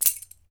Index of /90_sSampleCDs/E-MU Producer Series Vol. 7 – Old World Instruments (CD 2)/Drums&Percussion/Bell Rattle
BEL RATTL02R.wav